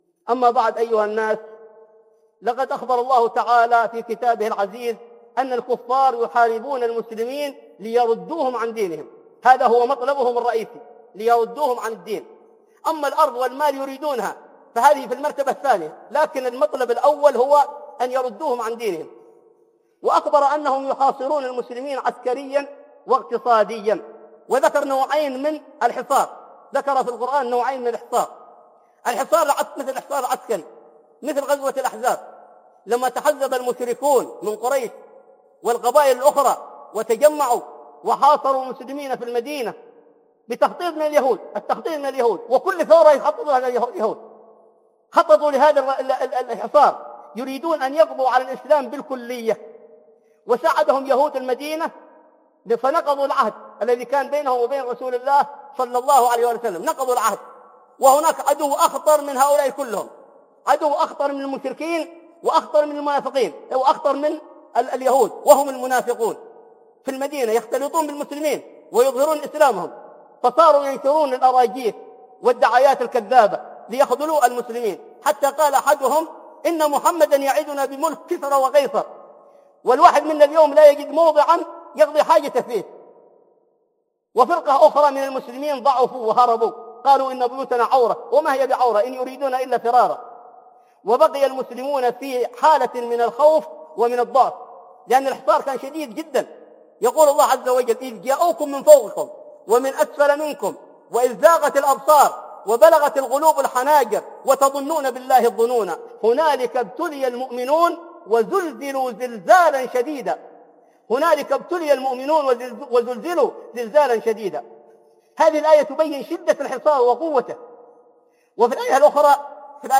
الحصار الاقتصادي عند الكفار - خطب